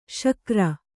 ♪ śakra